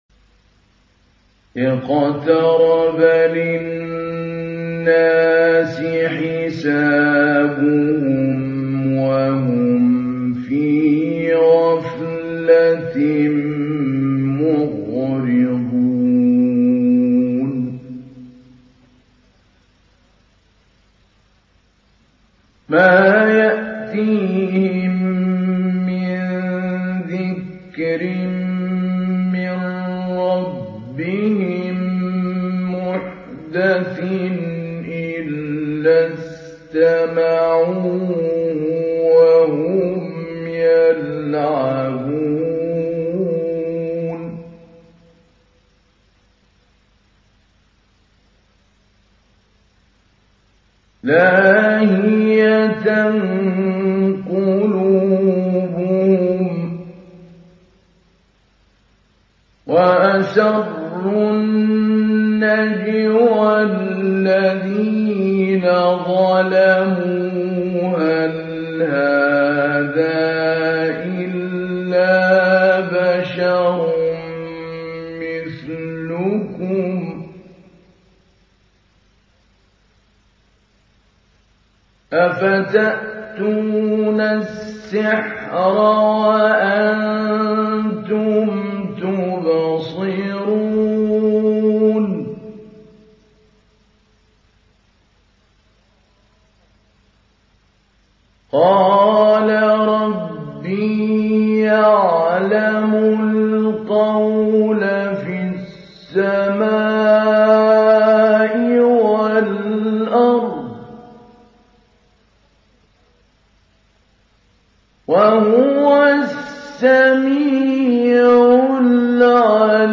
تحميل سورة الأنبياء mp3 بصوت محمود علي البنا مجود برواية حفص عن عاصم, تحميل استماع القرآن الكريم على الجوال mp3 كاملا بروابط مباشرة وسريعة
تحميل سورة الأنبياء محمود علي البنا مجود